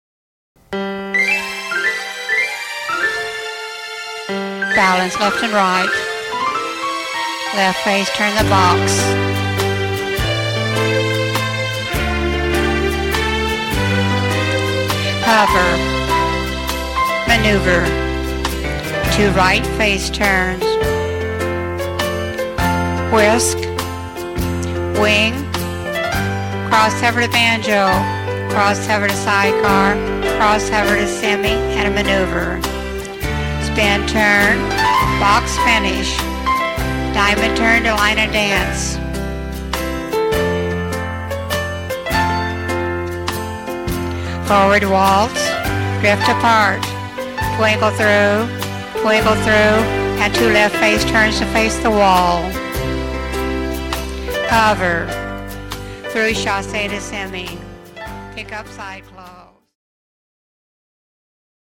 Cued Sample
Waltz